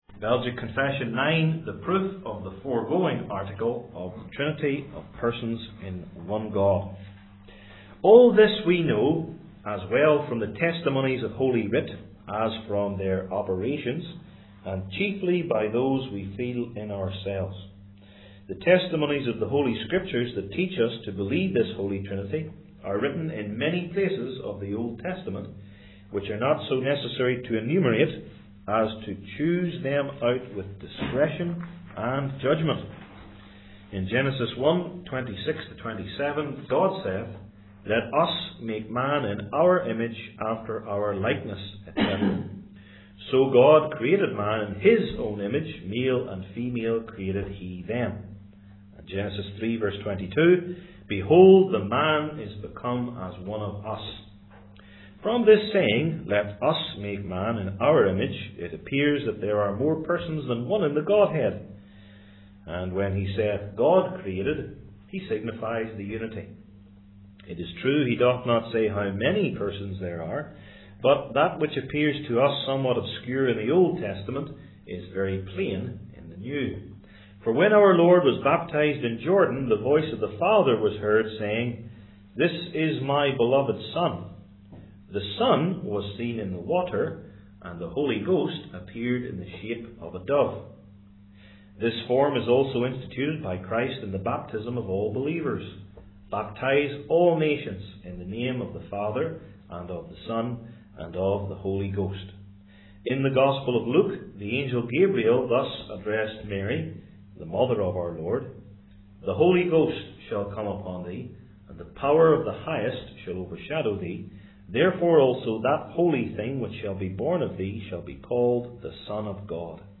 Passage: Ephesians 1:1-14 Service Type: Belgic Confession Classes